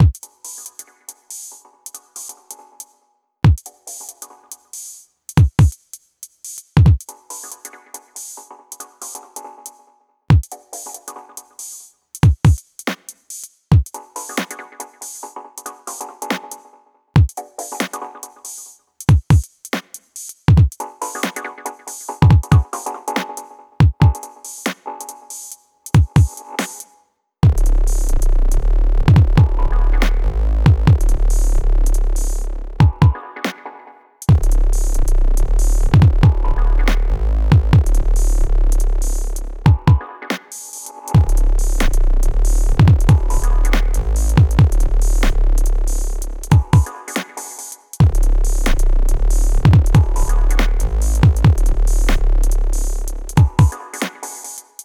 Made an audio test beat with the EMX-1 and the Dark Energy III, haven’t incorporated the Electribe sampler yet.
EDIT: Noticed day after that the levels of kick/bass are completely off lol. Mixed on headphones because it was too late to blast shit on monitors.